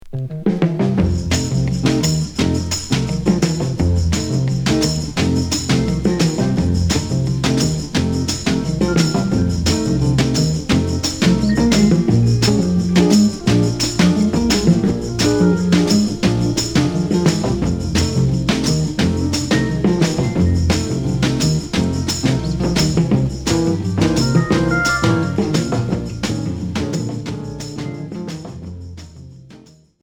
Groove easy listening Neuvième EP retour à l'accueil